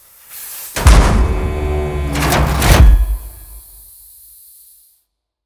shieldsoff2.wav